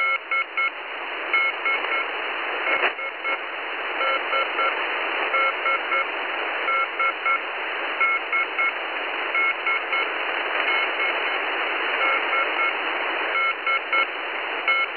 Time (UTC): 2235 Mode: LSB Frequency: 5295 Message: unusual 3 dots, or better a slight long dot plus two dots, endless sequence.